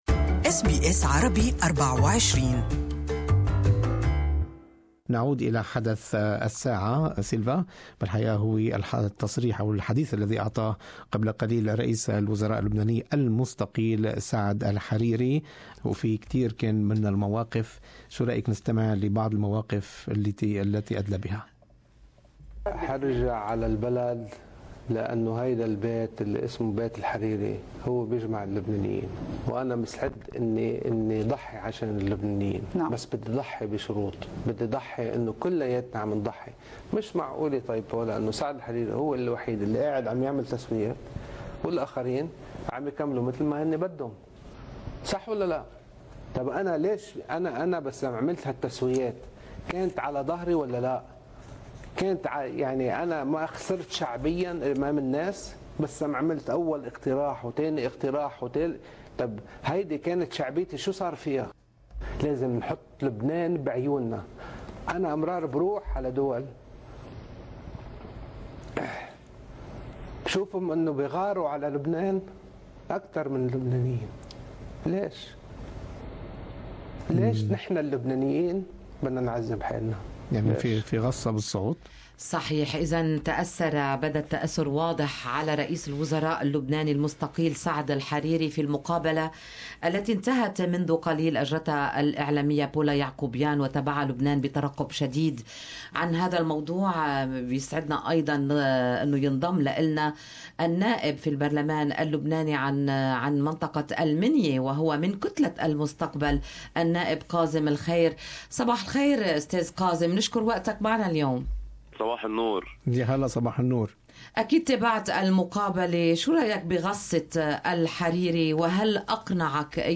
The Lebanese MP in "Future Movement" Kathem Al kheir speaks during "Good Morning Australia" about Saad Hariri's interview this morning.